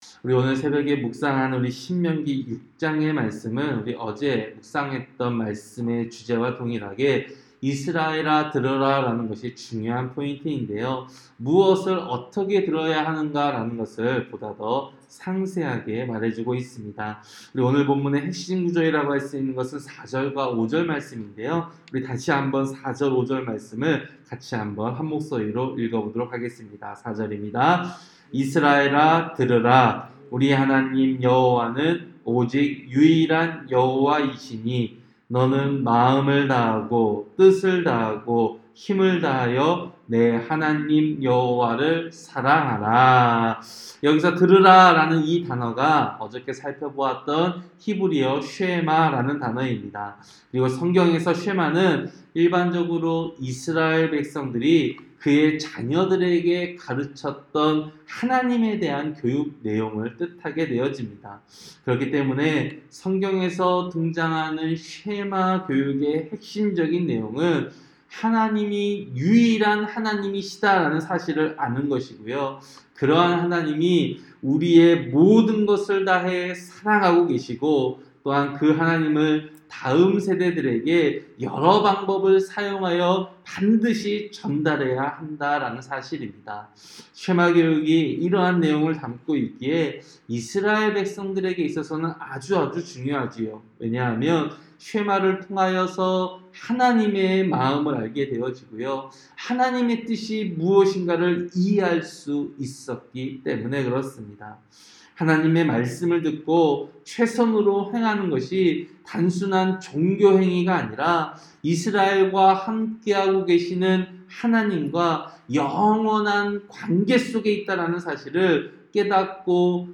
새벽설교-신명기 6장